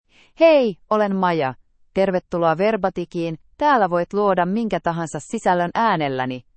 Maya — Female Finnish AI voice
Maya is a female AI voice for Finnish (Finland).
Voice sample
Listen to Maya's female Finnish voice.
Maya delivers clear pronunciation with authentic Finland Finnish intonation, making your content sound professionally produced.